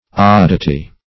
oddity - definition of oddity - synonyms, pronunciation, spelling from Free Dictionary
oddity \odd"i*ty\ ([o^]d"[i^]*t[y^]), n.; pl. Oddities